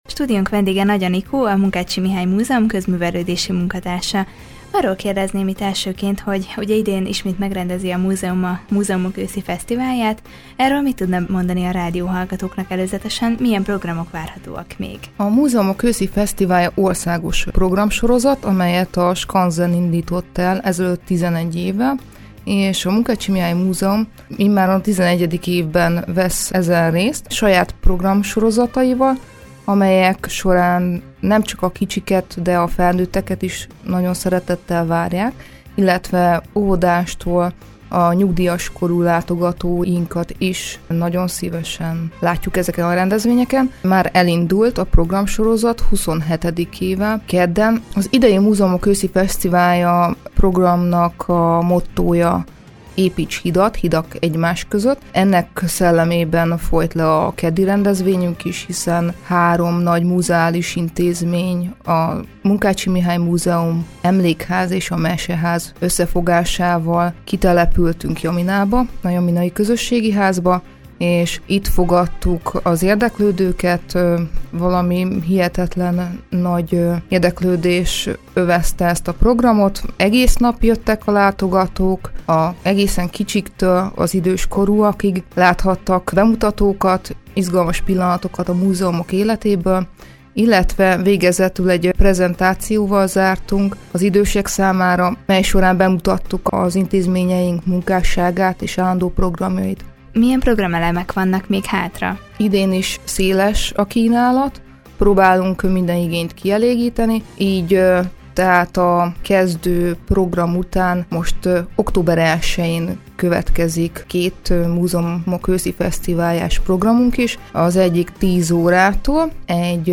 Vele beszélgetett tudósítónk a Múzeumok Őszi Fesztiváljáról és hogy a milyen programokkal készülnek a békéscsabaiak számára.